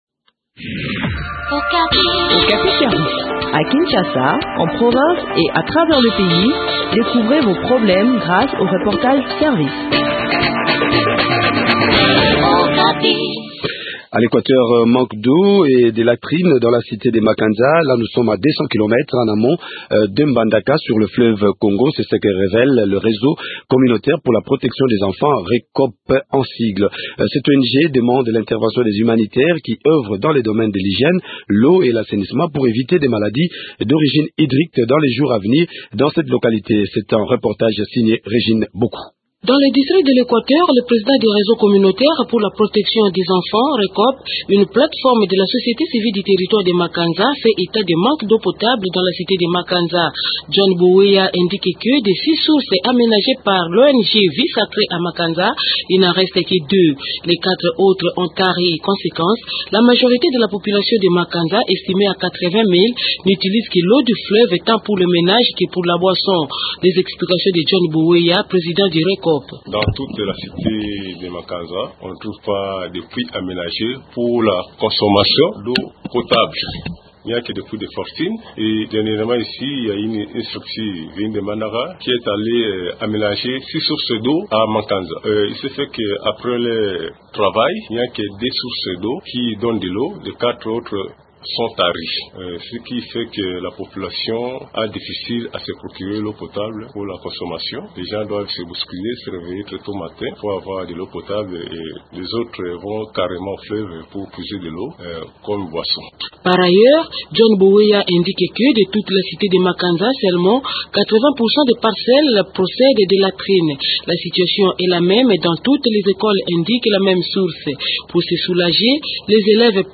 Le point de la situation sur place dans cet entretien